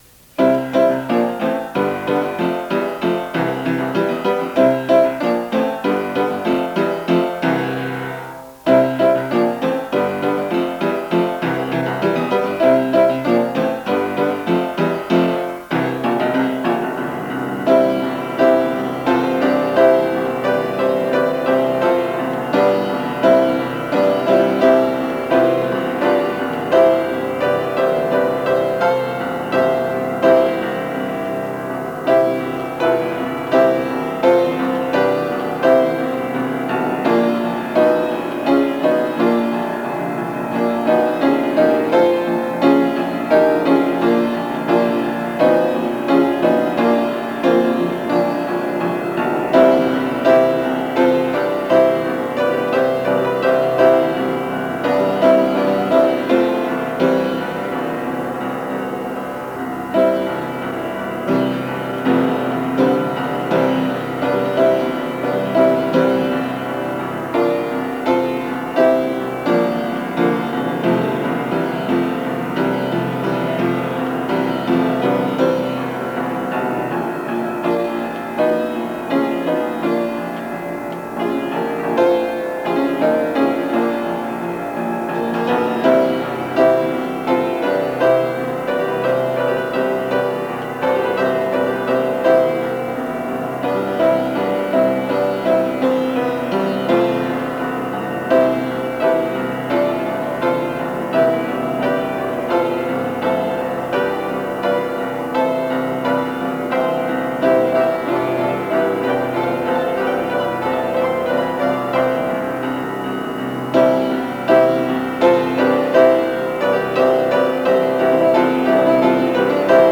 The following pieces consist of MIDI and/or MP3 files for the piano.
It was hard to get the midi to sound correct (I entered it by hand) but my live (MP3) recordings have mistakes - so you have a choice of accurate and uninspired, or inaccurate but at least not flat.
i Fast and steady. 2:54
The intention was for the left hand to do a piano equivalent of a bagpipe drone.